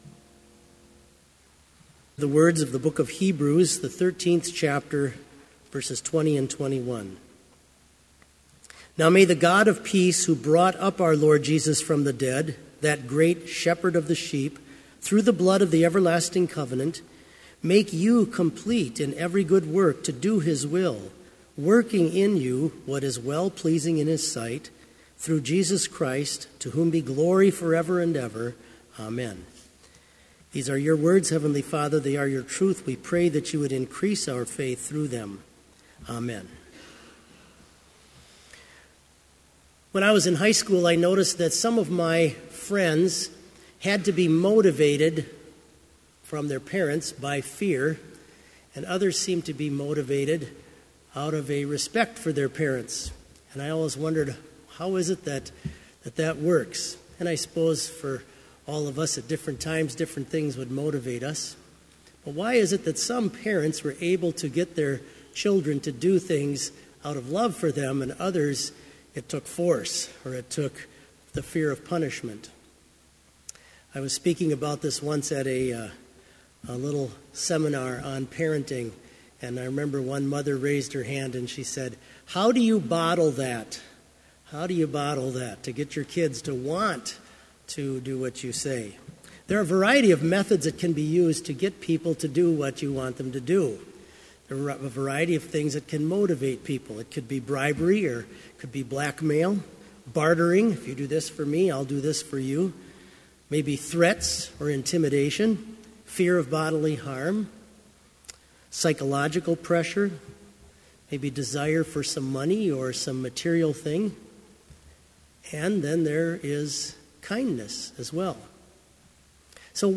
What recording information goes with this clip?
This Chapel Service was held in Trinity Chapel at Bethany Lutheran College on Monday, April 16, 2018, at 10 a.m. Page and hymn numbers are from the Evangelical Lutheran Hymnary.